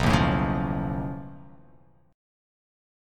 B9b5 chord